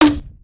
launch.wav